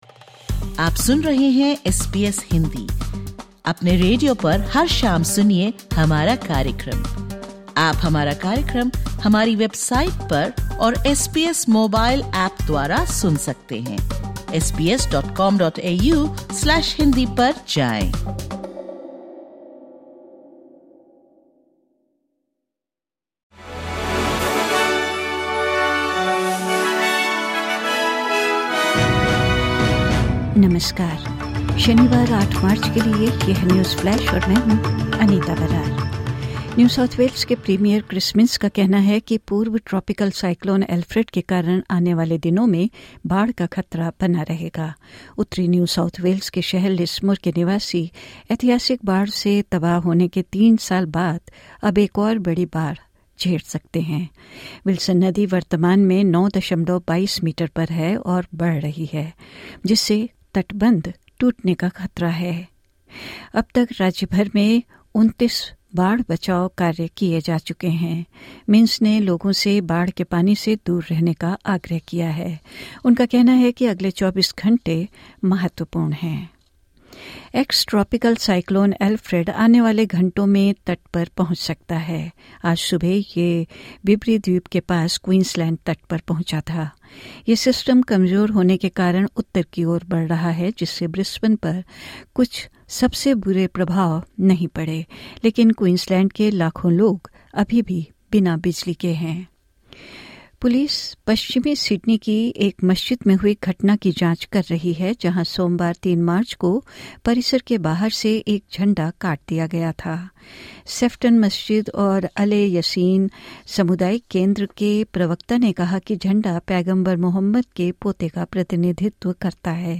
Listen to the top News of 08/03/2025 from Australia in Hindi.